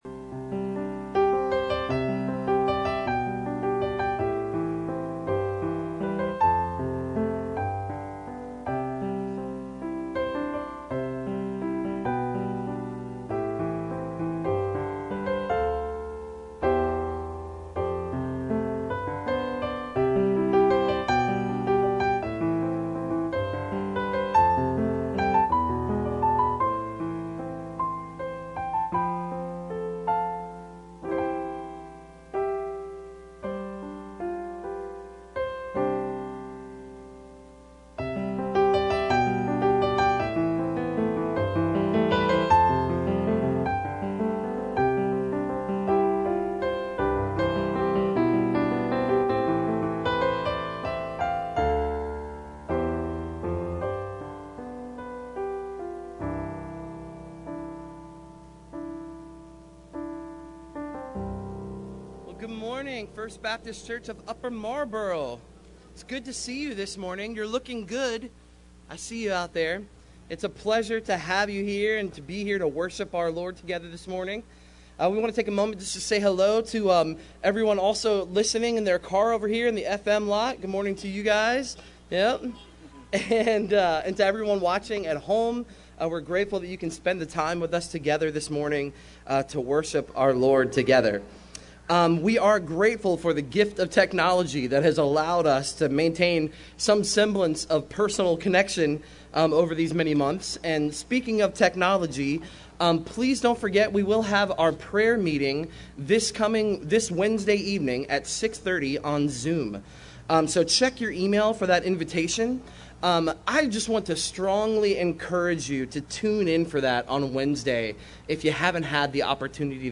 SERVICE_Wisdom-from-Above-or-Below.mp3